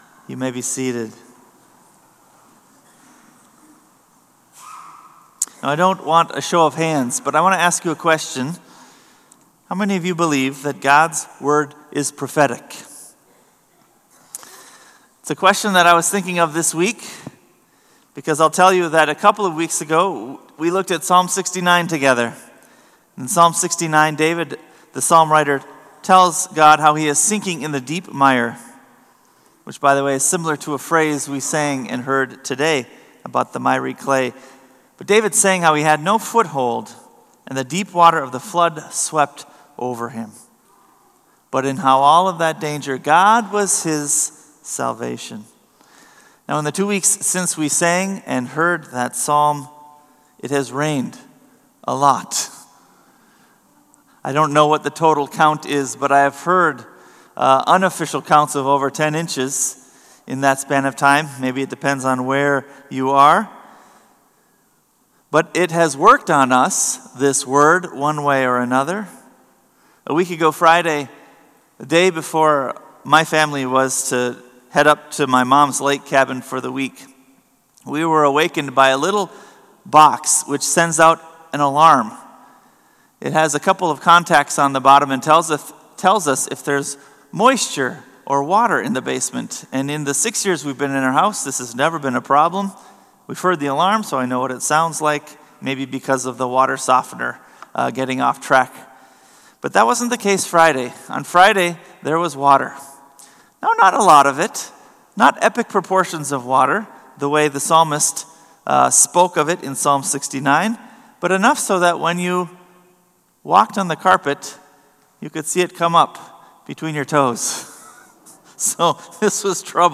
Sermon “Christ, the Solid Rock”